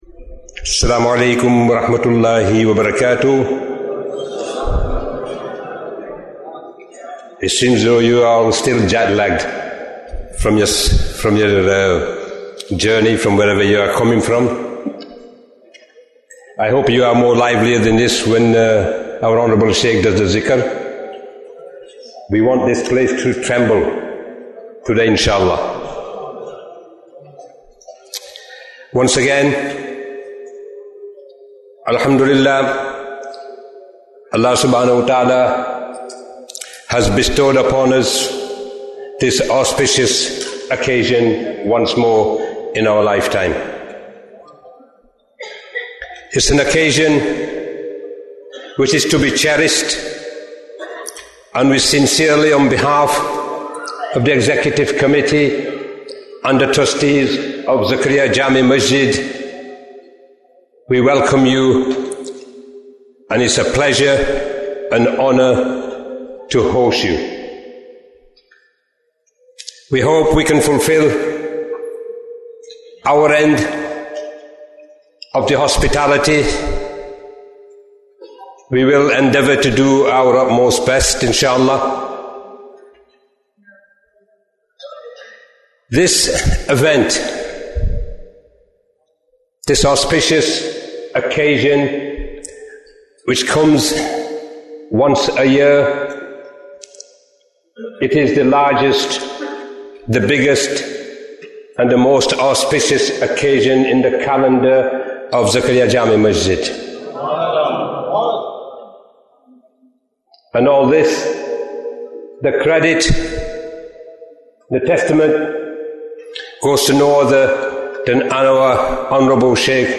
Annual Ijtema Programme 2024 Bayan, 75 minutes25th December, 2024